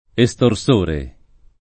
estorsore [ e S tor S1 re ]